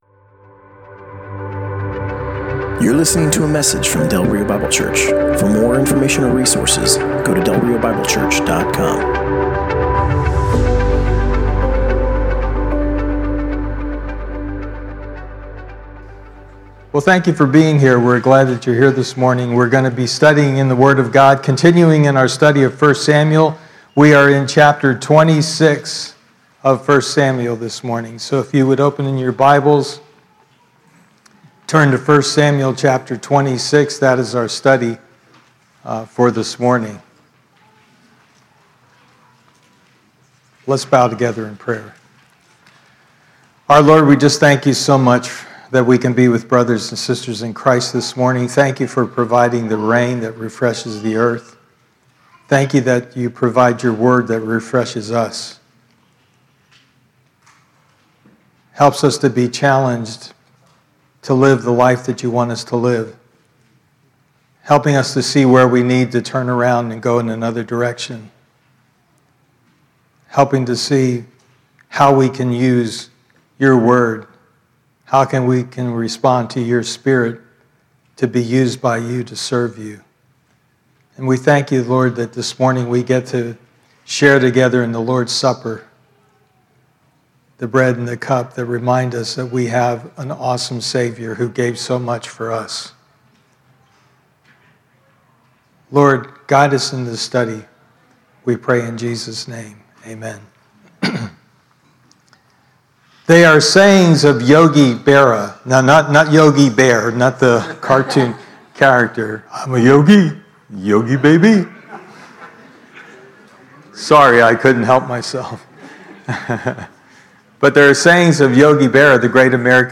Passage: 1 Samuel 26: 1-25 Service Type: Sunday Morning